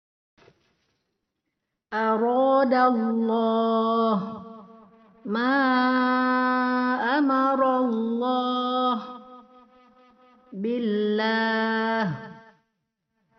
a. Dibaca tebal pada lafaz (Allah) huruf sebelumnya berharokat Fathah atau Dhommah.
b. Dibaca tipis pada lafazh (Allah) huruf sebelumnya Kasrah.